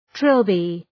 Προφορά
{‘trılbı}